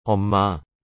O que significa e como pronunciar “mamãe” em coreano?
Imagine dizer “é um” de um jeito suave, seguido por “má”.